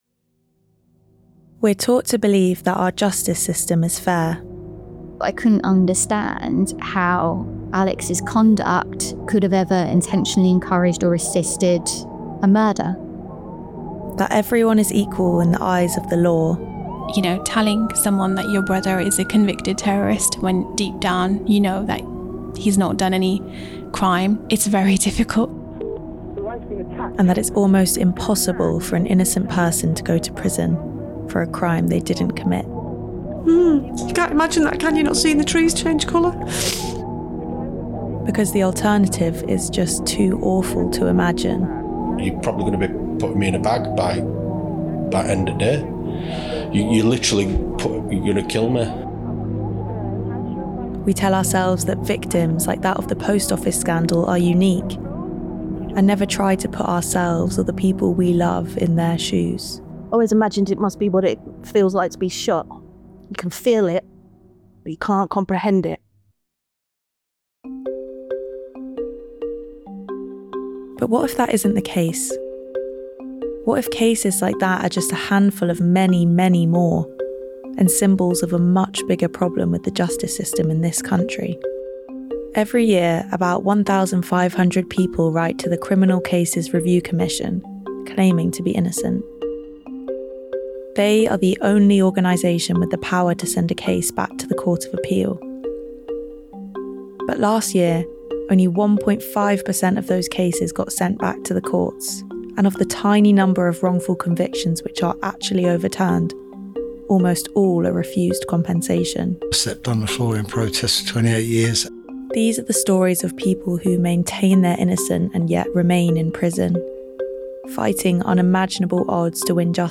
The-Overturn-Trailer.mp3